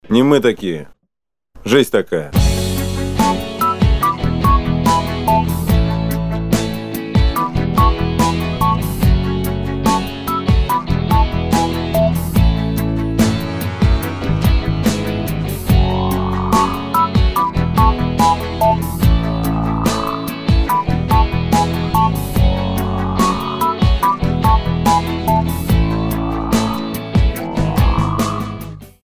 Рок рингтоны
Гитара